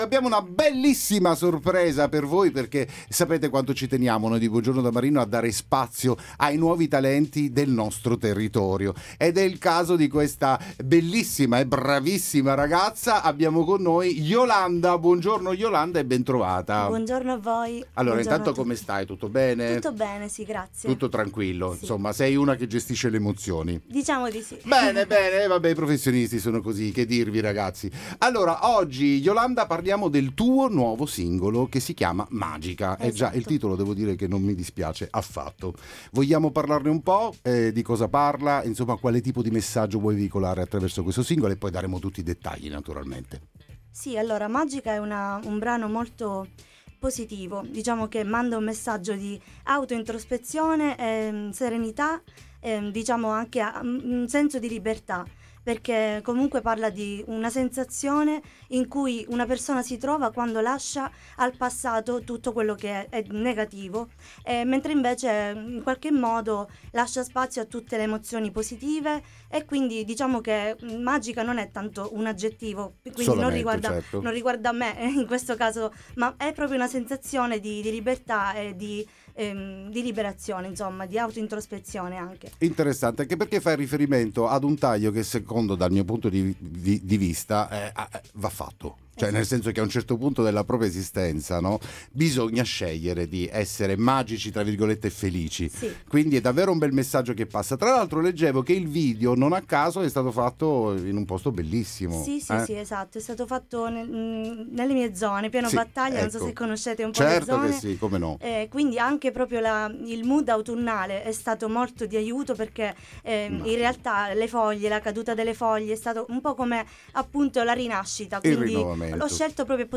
Buongiorno da Marino Interviste